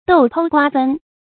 豆剖瓜分 dòu pōu guā fēn 成语解释 豆出荚；瓜切开。比喻被分割；被侵占。